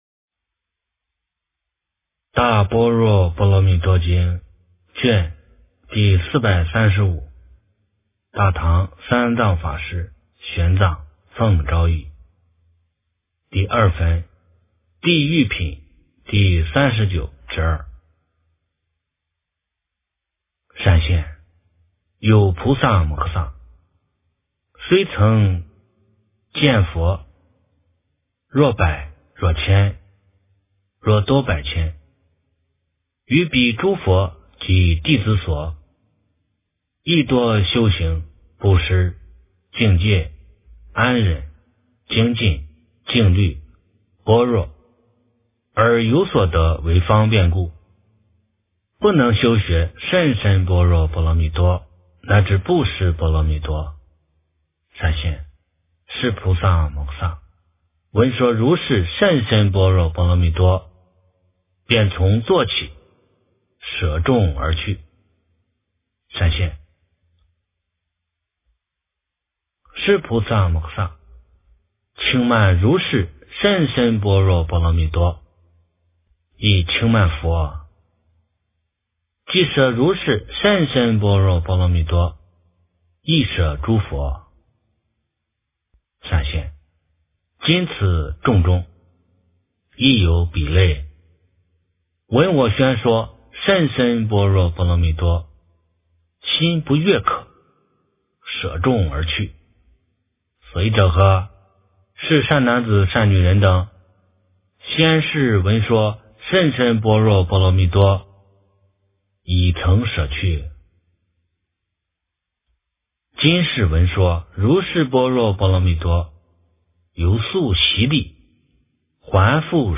大般若波罗蜜多经第435卷 - 诵经 - 云佛论坛